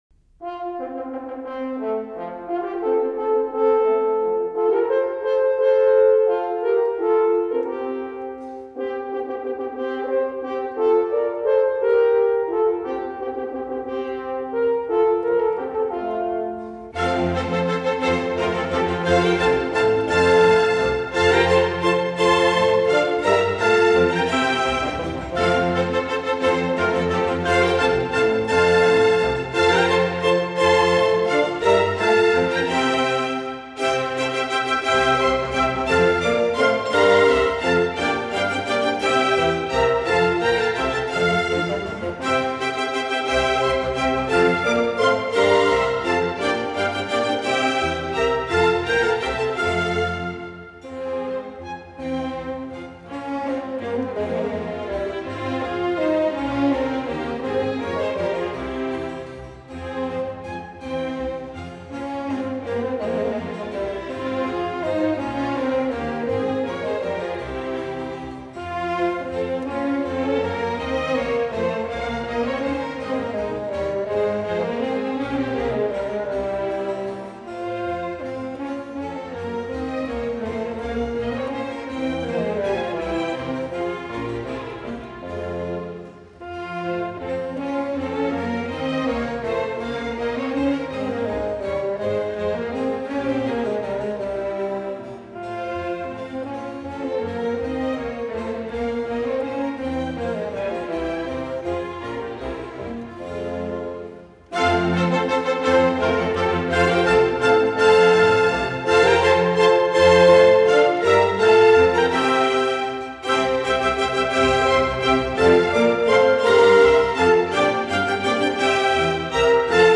6. Minuet[2:05]
小步舞曲